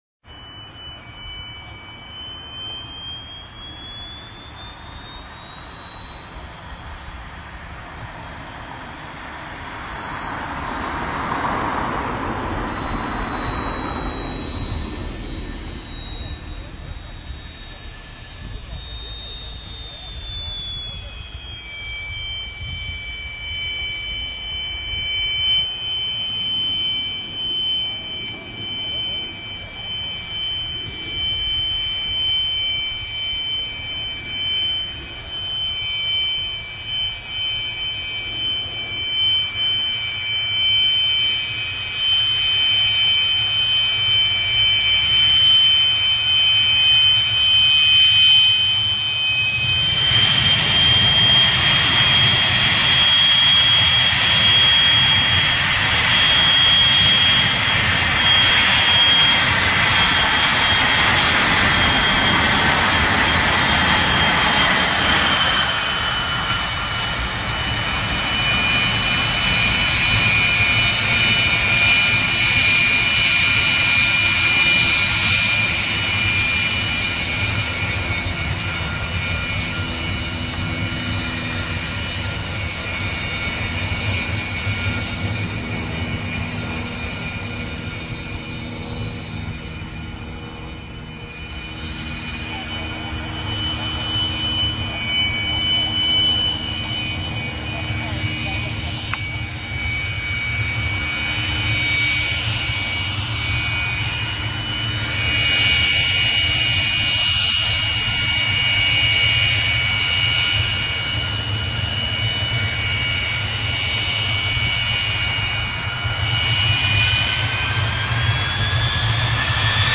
4 Avion
Tags: martillo